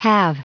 Prononciation du mot halve en anglais (fichier audio)
Prononciation du mot : halve